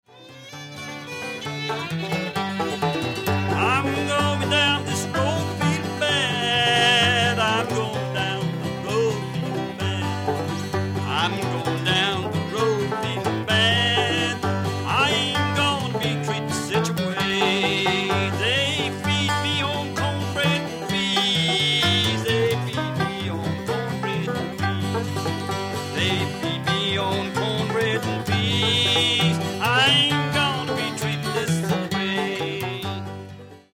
Down Yonder: Old Time String Band Music from Georgia
This is a traditional Southern folk song, known in the Carolinas and Georgia.
fiddle and vocal
guitar